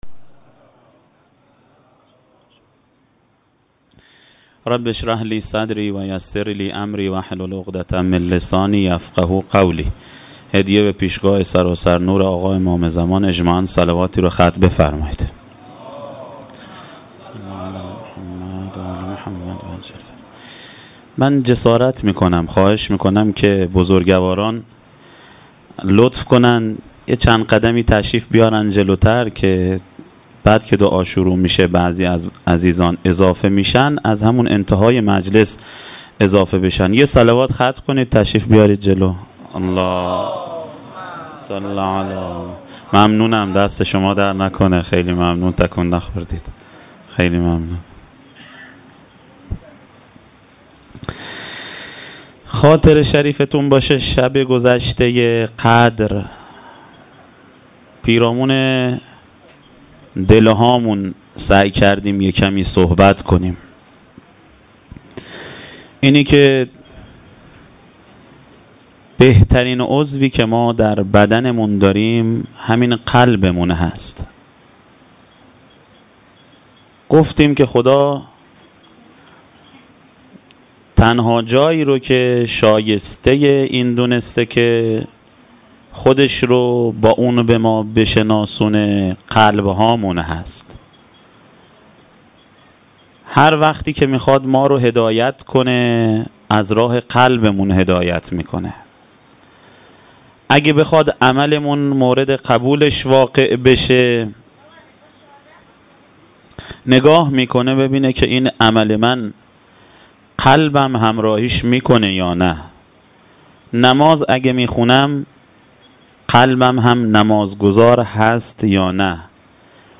سخنرانی شب 21ماه مبارک.